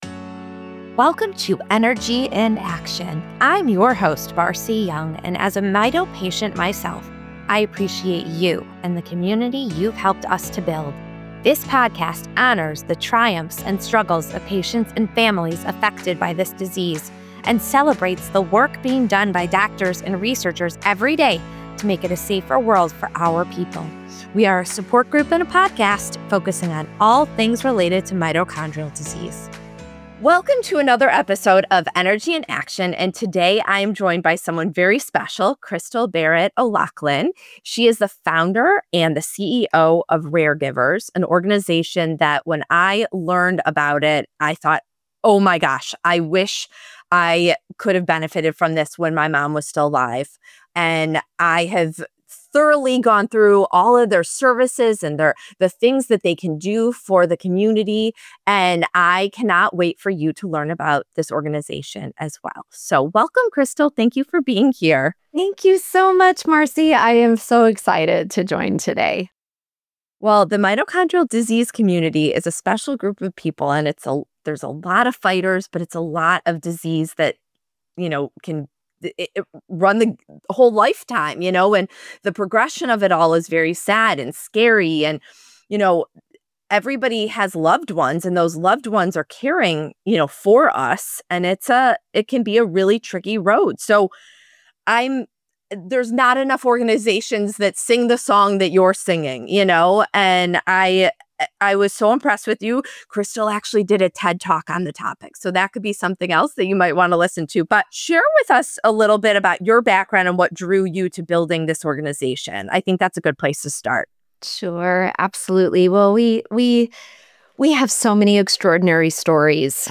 for an honest, uplifting conversation about what it really takes to care for someone who is living with a lifelong, often‑progressive illness.